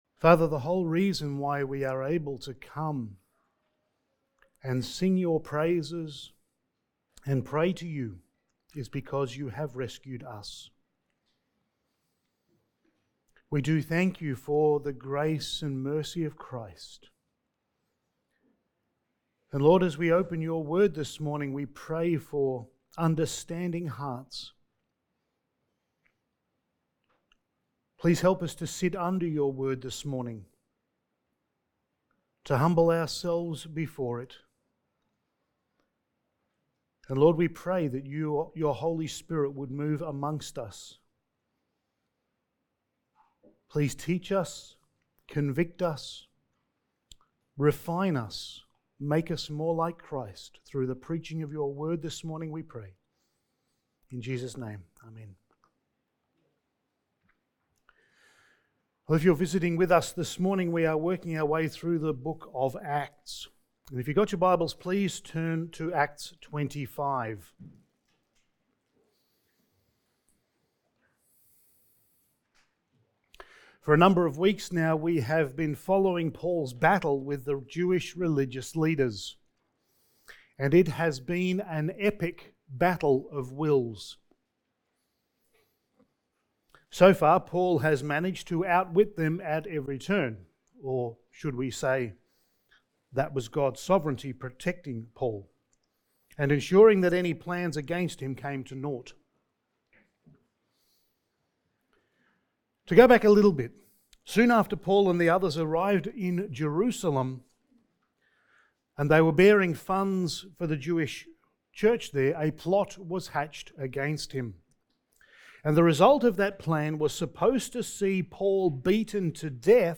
Passage: Acts 25:1-22 Service Type: Sunday Morning